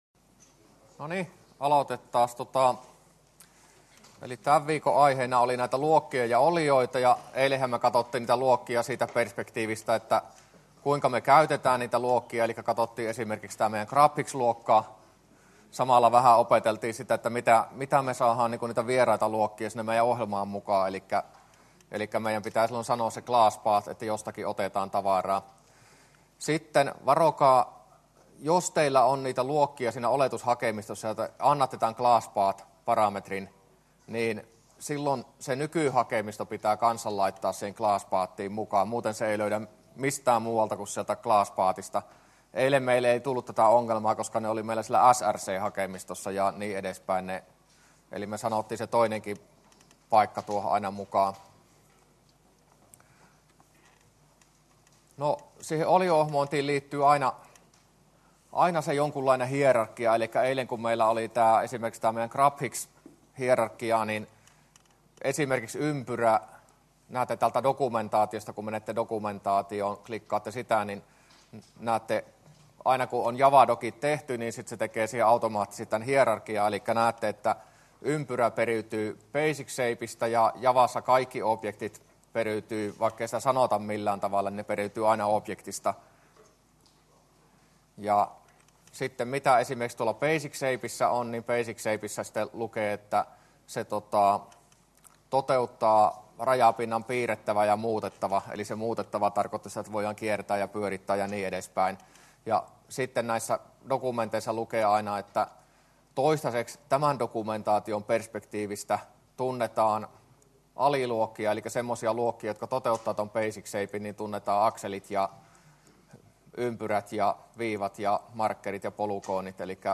luento10a